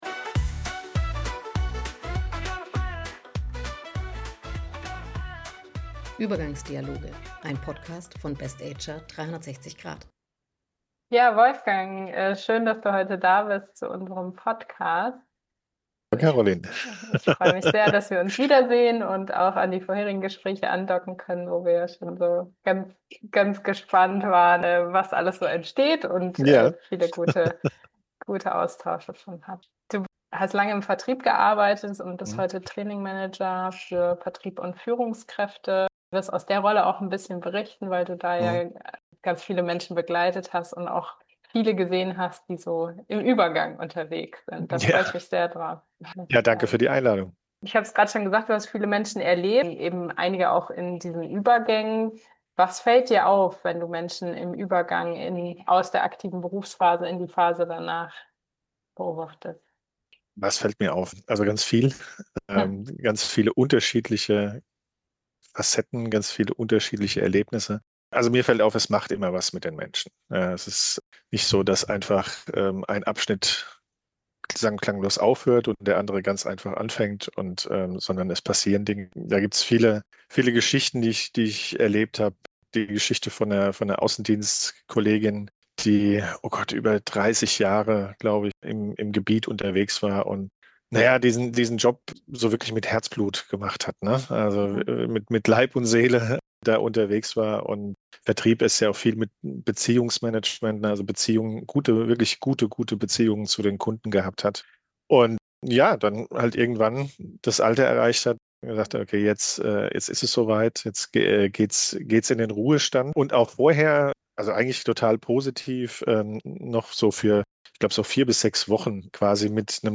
Ein spannendes Interview zum Thema Übergang in die neue Lebensphase